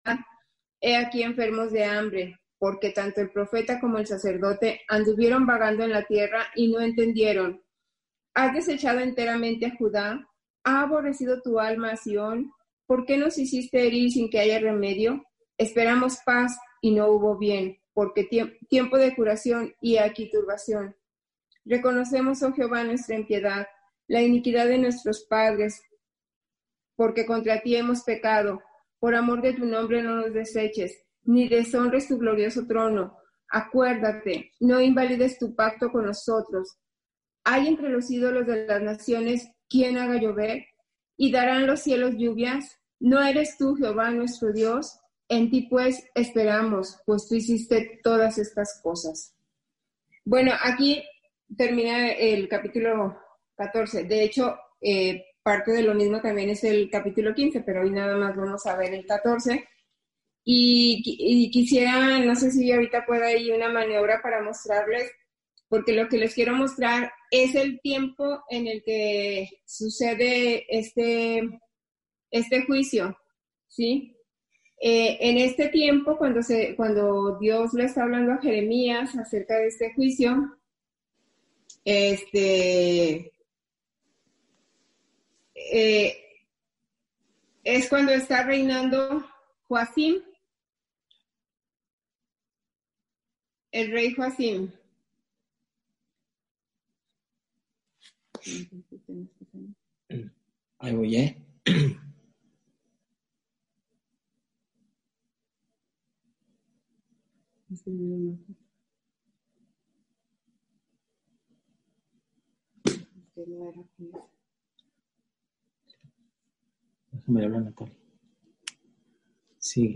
Estudiamos el mensaje por motivo de la sequía que le fue dado al pueblo de Israel. Estudio